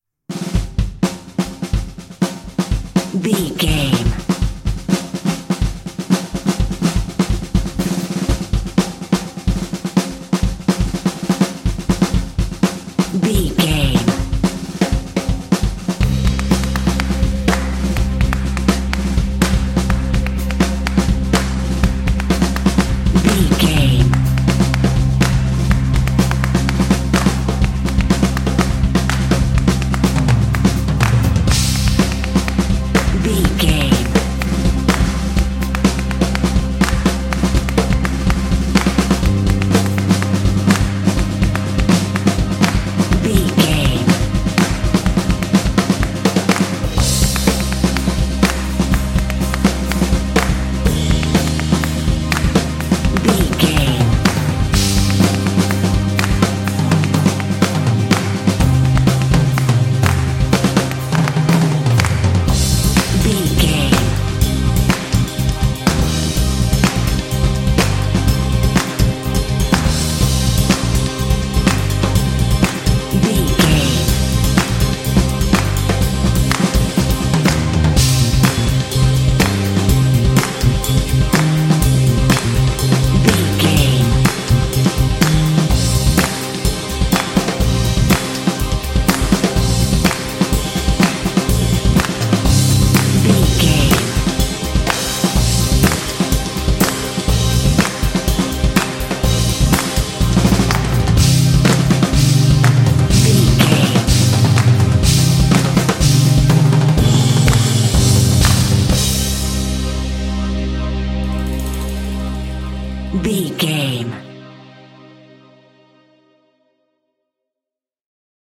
Epic / Action
Fast paced
Aeolian/Minor
bass guitar
synthesiser
strings
drumline
contemporary underscore